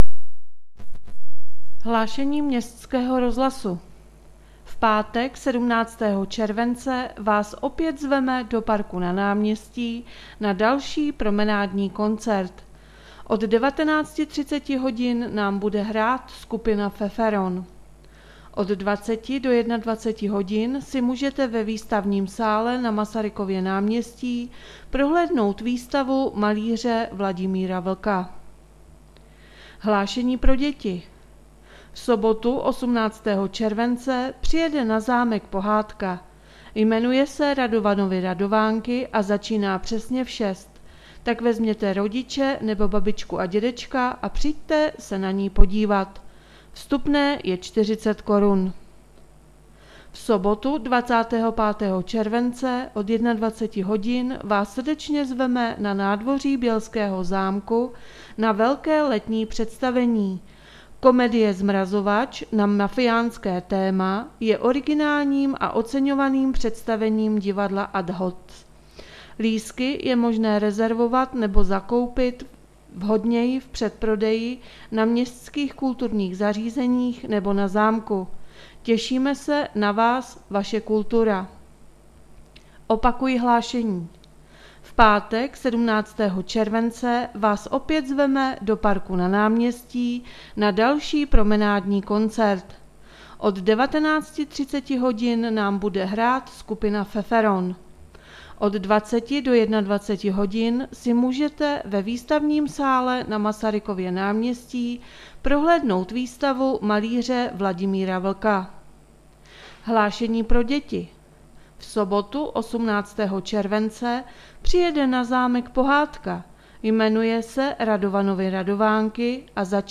Hlášení městského rozhlasu 15.7.2020